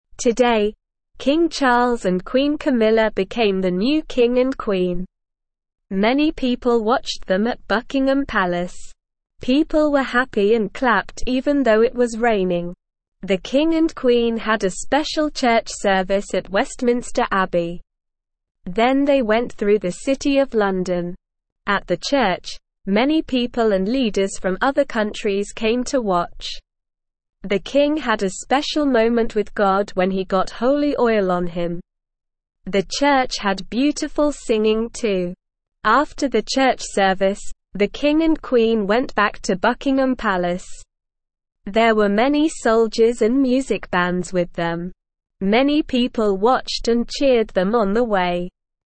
Slow
English-Newsroom-Beginner-SLOW-Reading-New-King-and-Queen-Celebration-Day.mp3